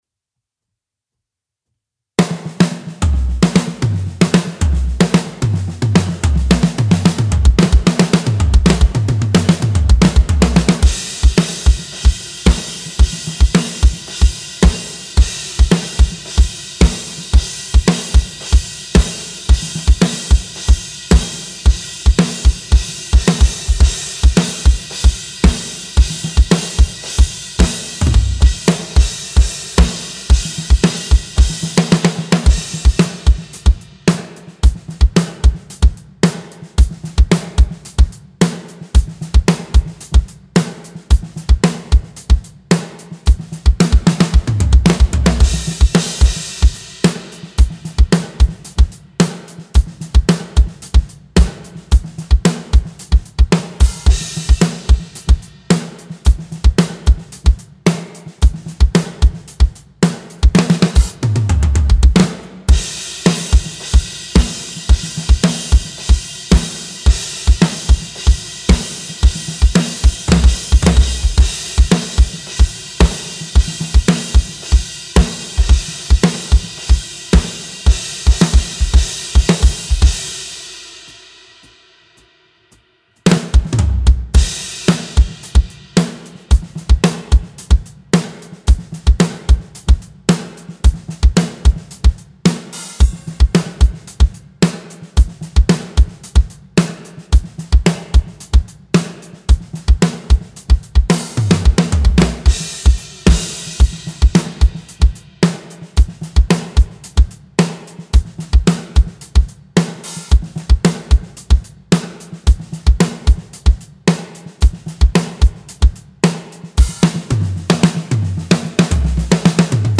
Genre:Rock, Metal
Tempo:111 BPM (4/4)
Kit:Sonor SQ2 beech 22"
Mics:18 channels